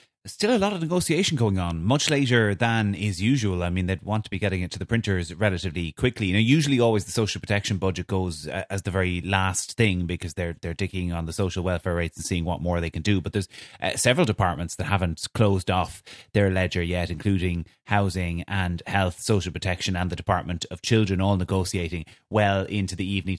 Political Correspondent